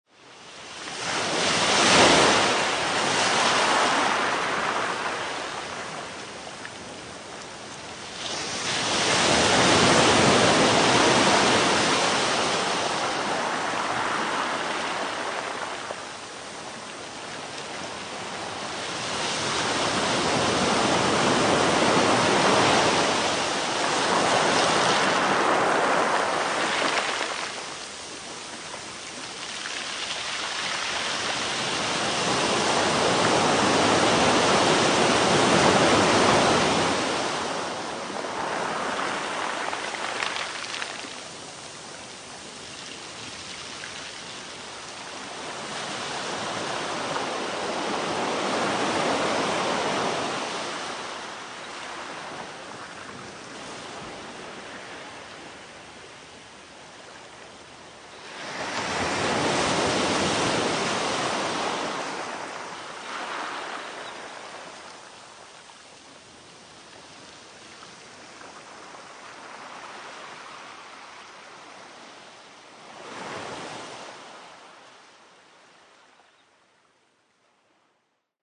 Sounds of nature - Sea waves
• Category: Sea and waves